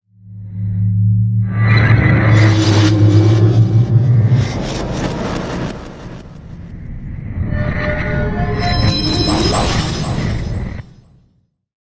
科幻的声音效果 (22)
描述：声音设计元素。 从ZOOM H6录音机和麦克风Oktava MK01201领域录制的效果，然后处理。
标签： 未来 托管架 无人驾驶飞机 金属制品 金属 过渡 变形 可怕 破坏 背景 游戏 黑暗 电影 上升 恐怖 开口 命中 噪声 转化 科幻 变压器 冲击 移动时 毛刺 woosh 抽象的 气氛
声道立体声